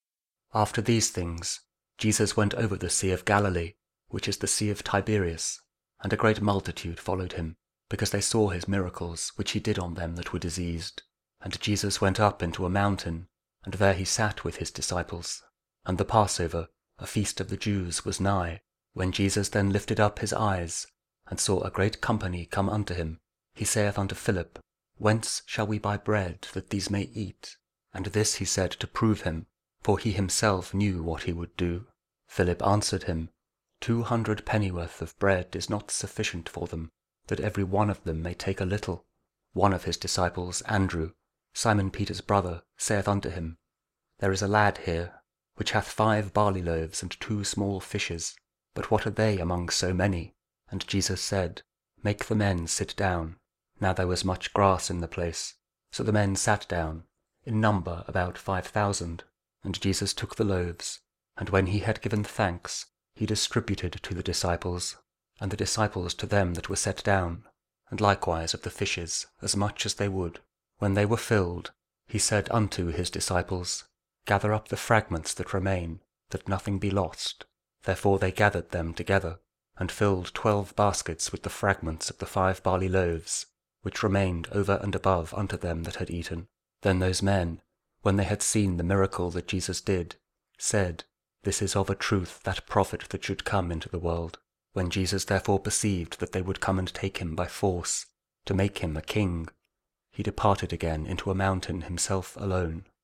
John 6: 1-15 Audio Bible KJV | King James Audio Bible | Daily Verses